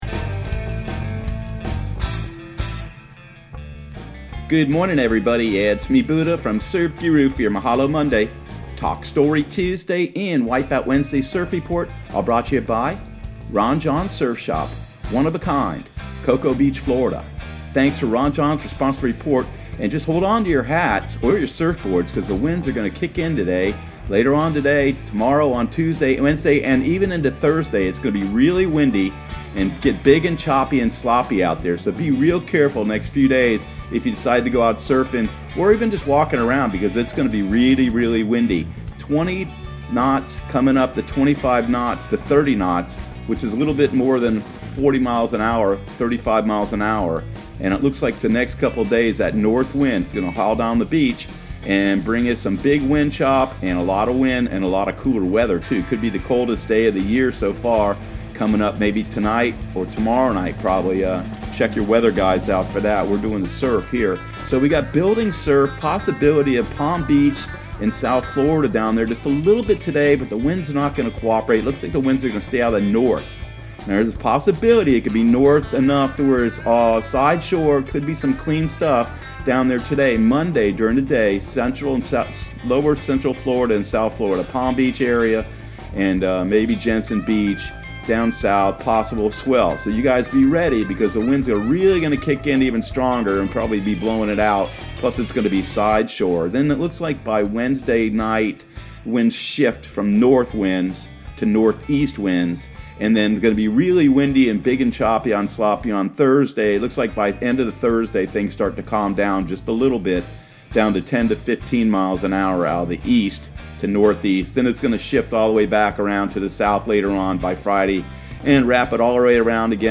Surf Guru Surf Report and Forecast 01/20/2020 Audio surf report and surf forecast on January 20 for Central Florida and the Southeast.